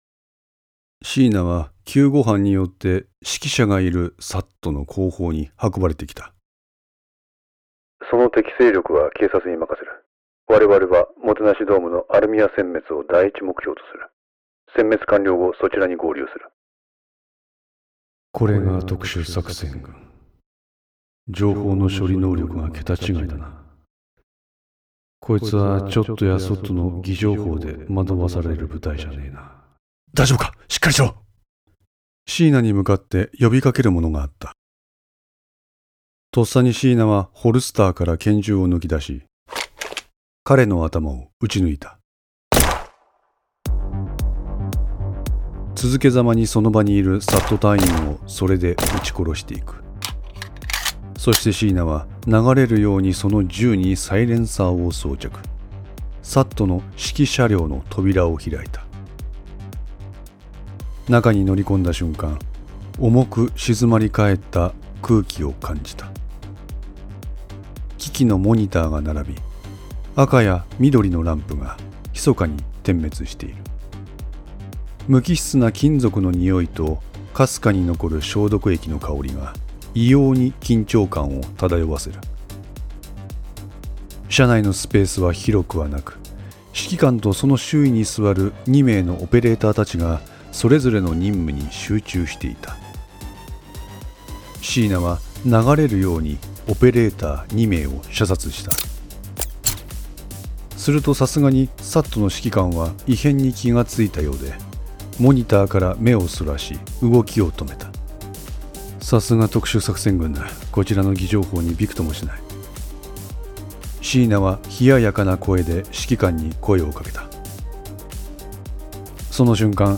オーディオドラマ「五の線３」